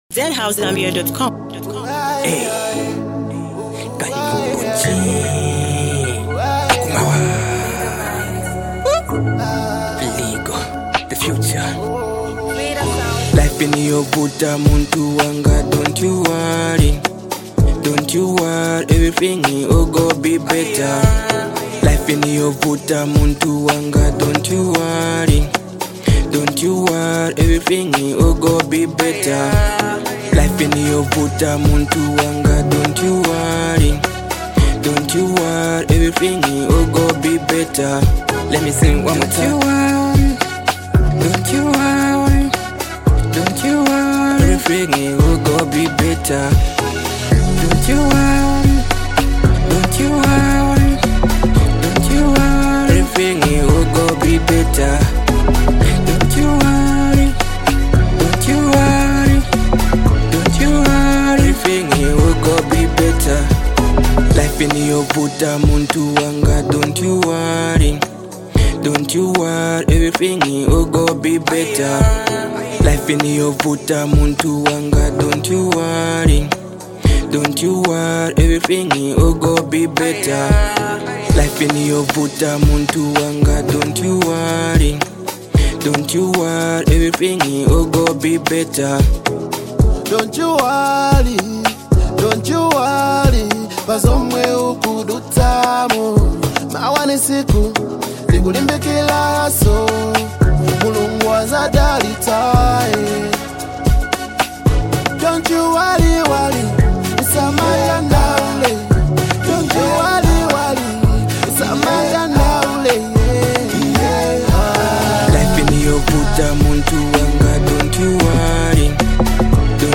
a smooth, heartfelt track